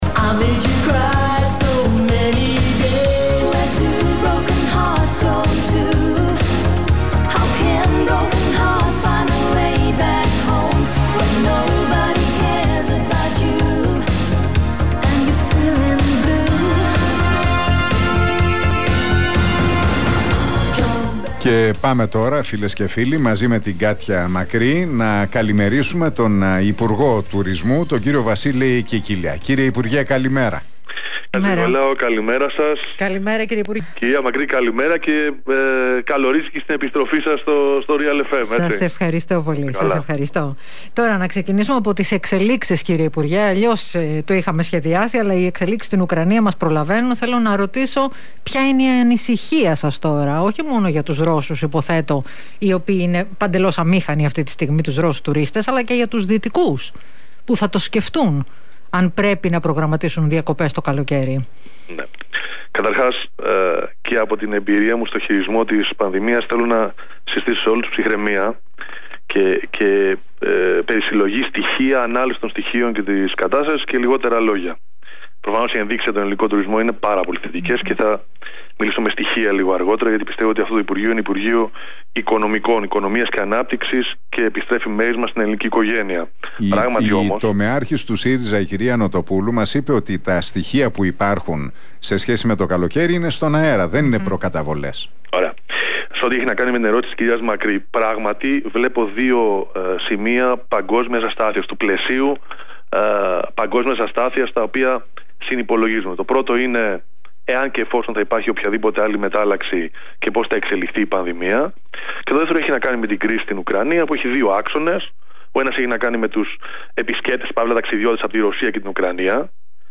Κικίλιας στον Realfm 97,8: Είμαστε το 5ο παγκόσμιο brand στον τουρισμό - Τι απάντησε για την κρίση στην Ουκρανία